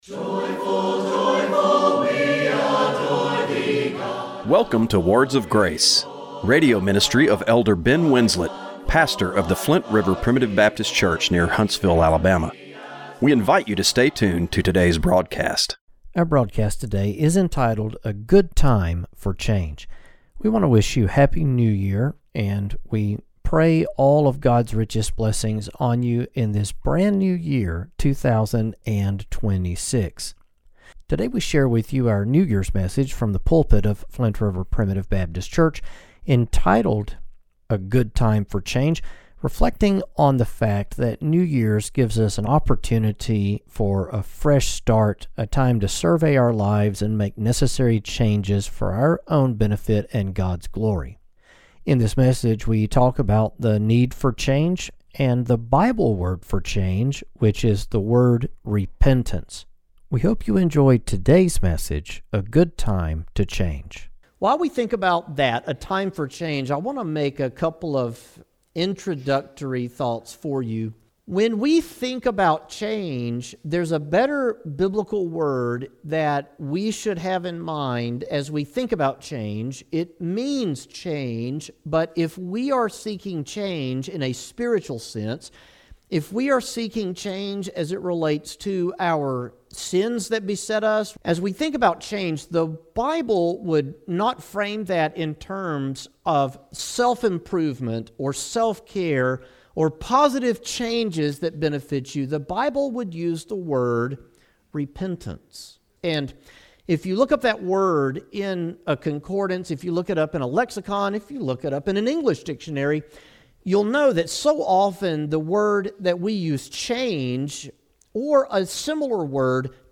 Radio broadcast for January 4, 2025.